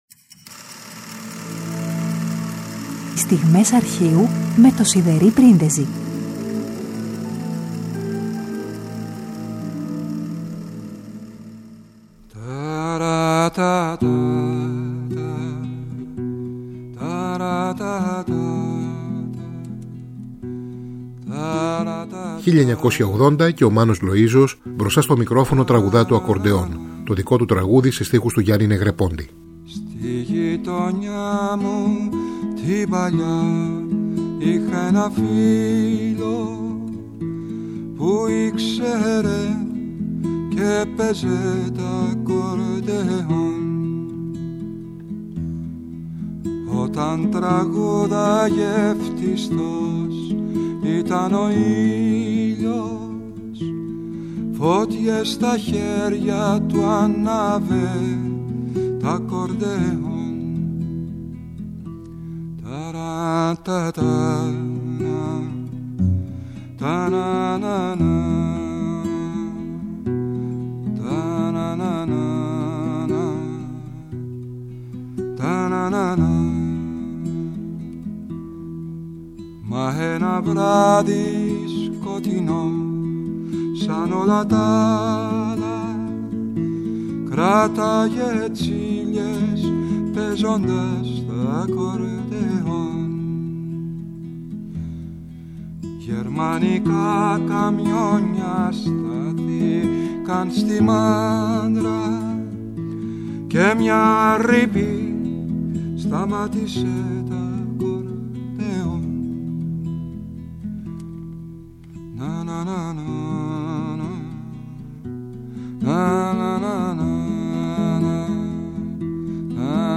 τραγούδια ηχογραφημένα στο ραδιόφωνο